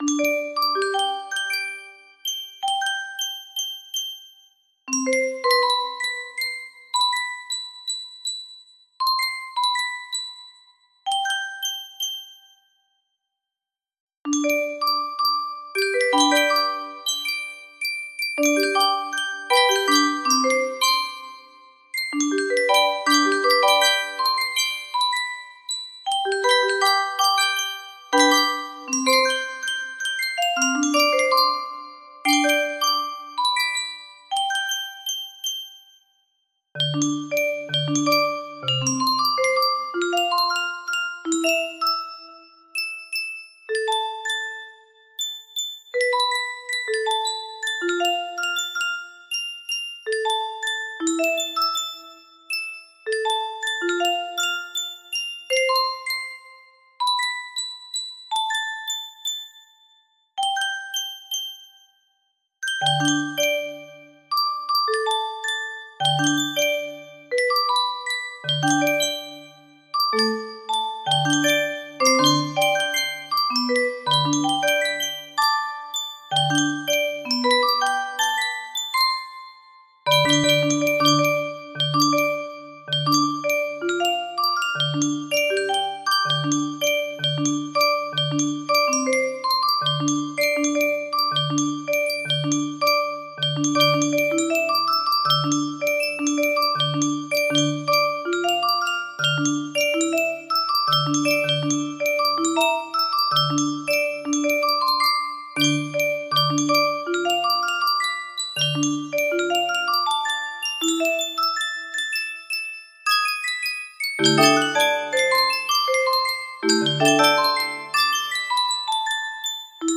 Full range 60
This is an original song composition.